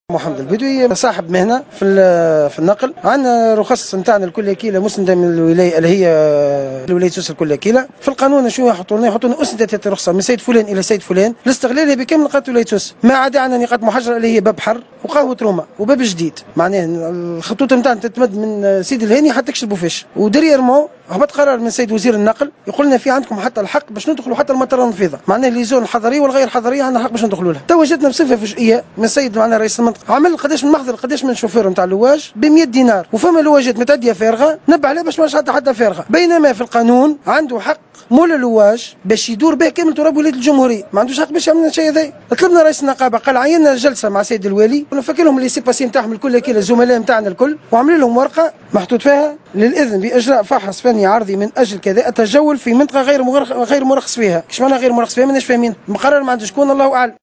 أحد المحتجين